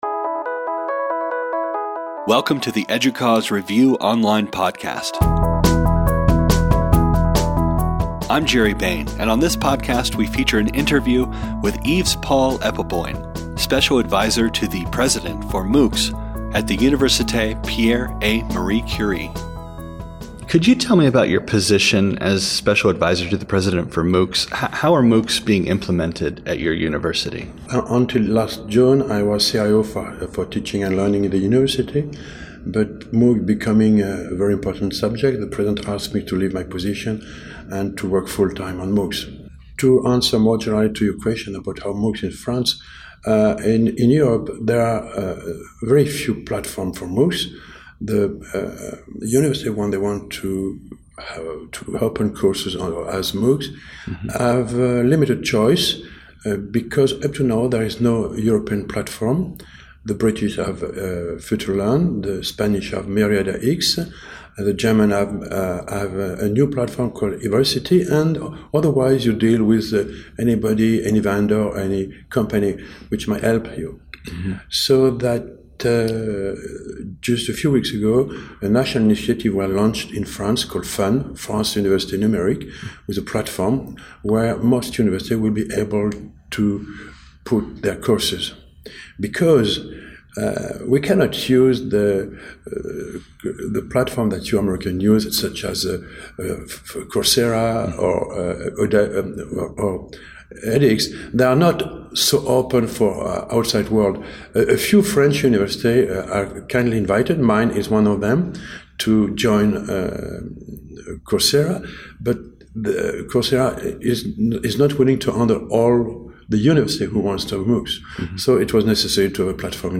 EDUCAUSE | Podcasts, Interview